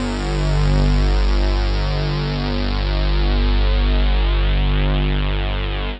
Index of /90_sSampleCDs/Trance_Explosion_Vol1/Instrument Multi-samples/Angry Trance Pad
G2_angry_trance_pad.wav